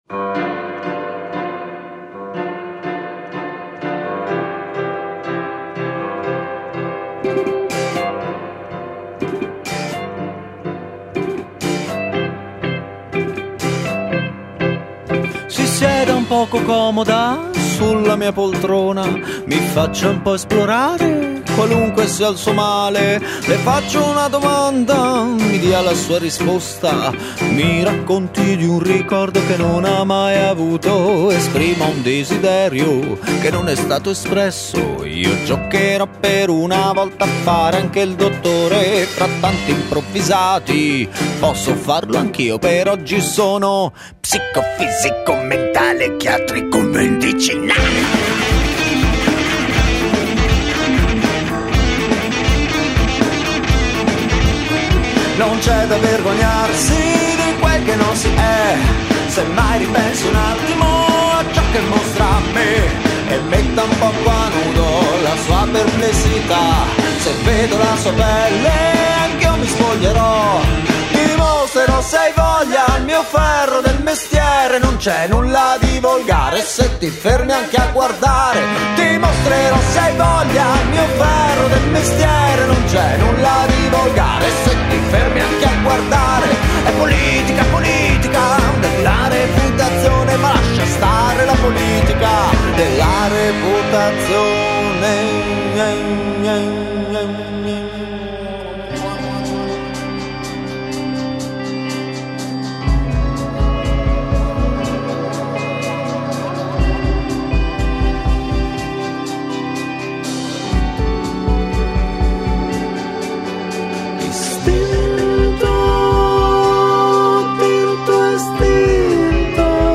GenerePop